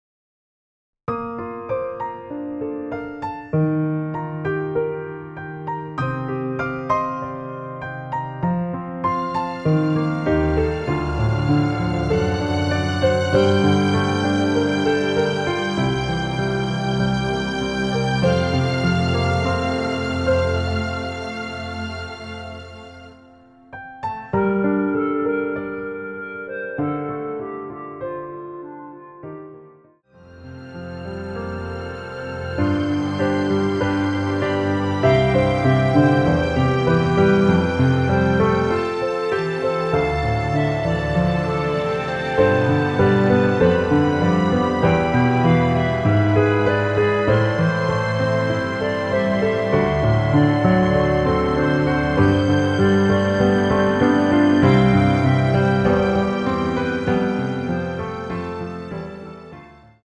원키에서(-2)내린 멜로디 포함된 MR입니다.
Gm
앞부분30초, 뒷부분30초씩 편집해서 올려 드리고 있습니다.
중간에 음이 끈어지고 다시 나오는 이유는